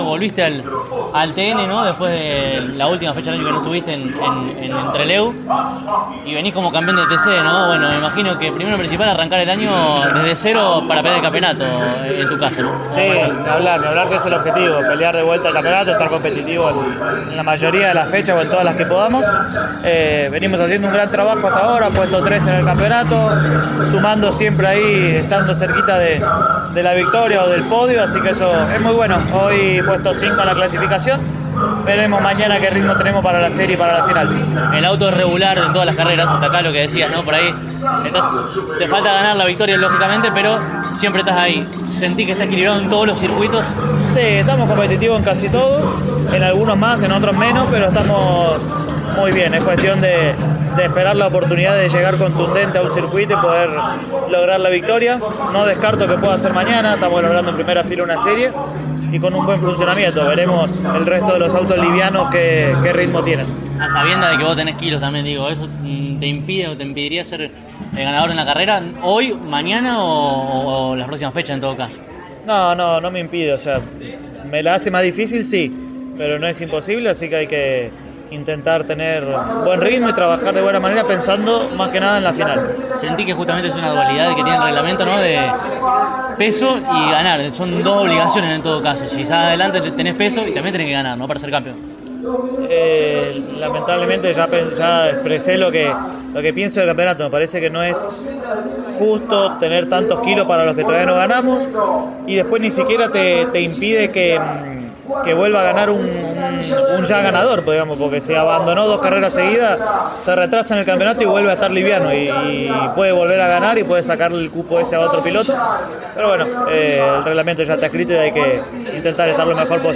En Concordia, en el marco de la tercera fecha del año del Turismo Nacional, Julián Santero dialogó in extenso con CÓRDOBA COMPETICIÓN.